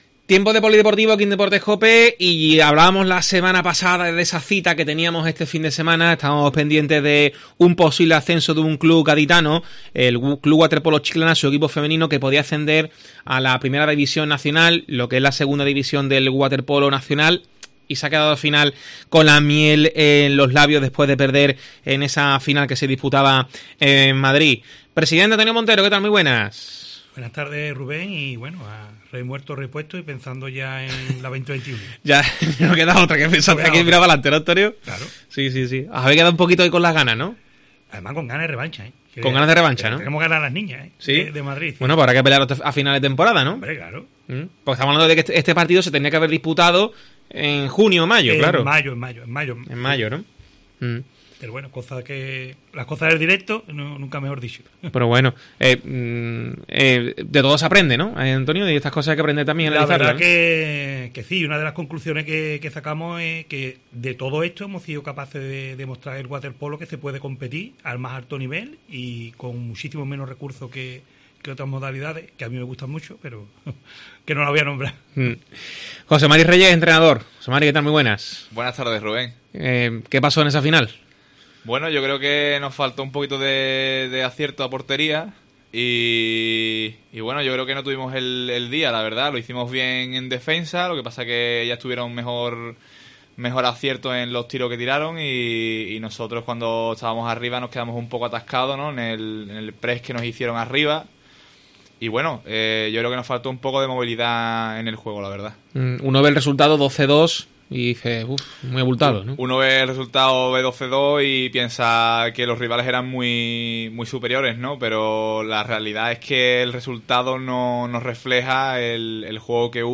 AUDIO: Entrevista a los integrantes del Club Waterpolo Chiclana